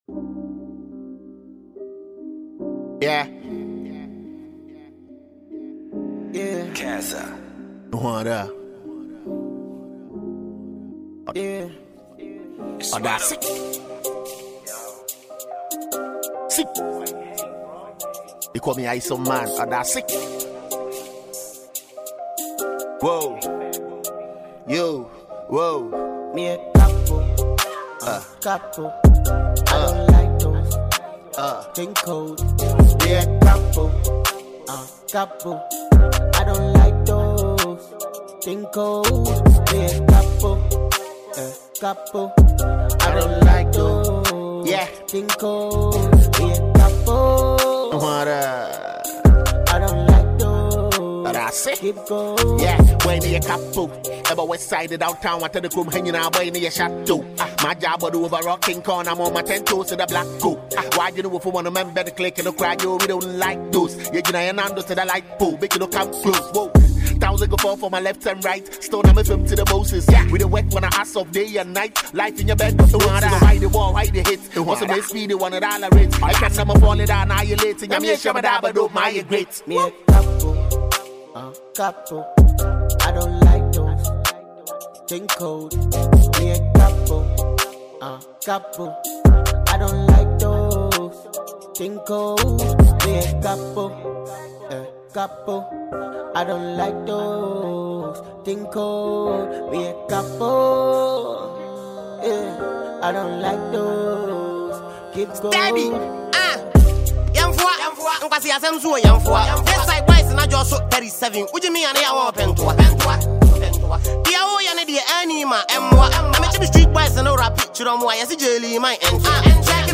Talented Ghanaian rapper